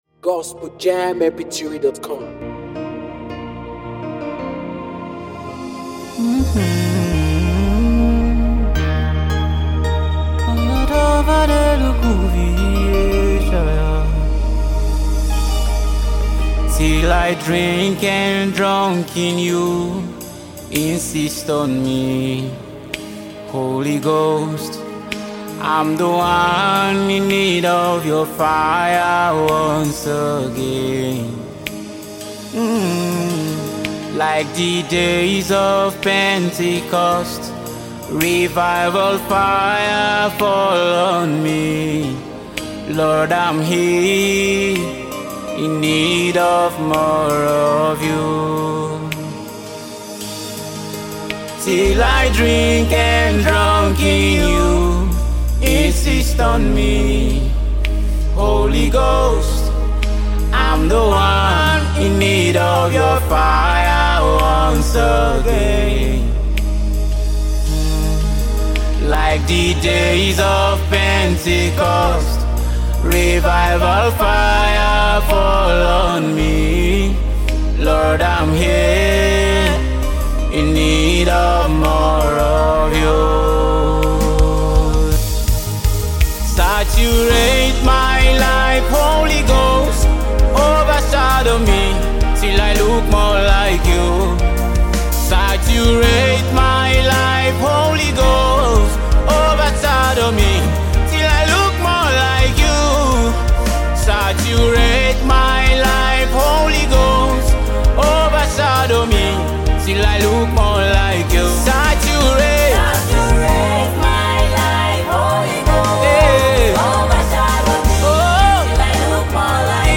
NUMBER 1 AFRICA GOSPEL PROMOTING MEDIA
Nigerian gospel music superstar